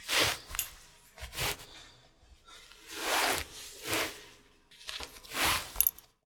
household
Cloth Moving Belt On Trousers